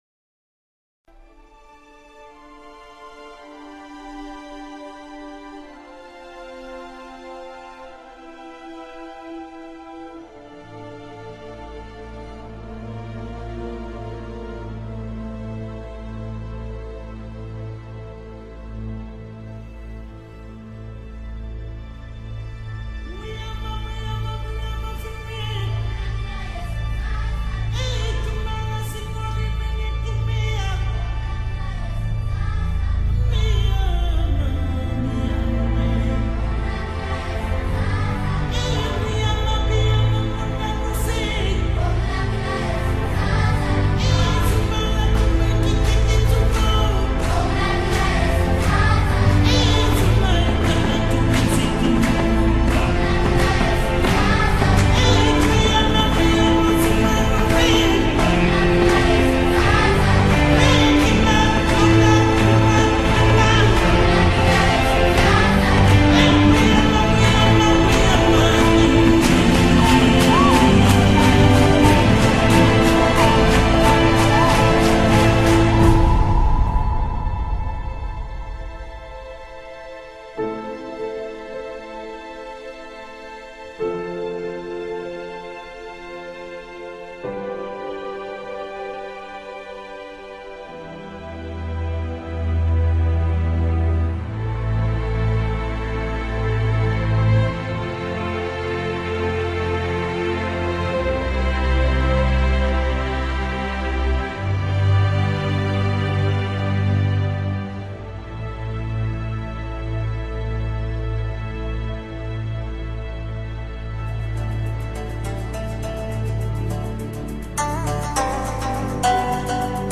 WonderfulAfricaChillOutMusic.mp3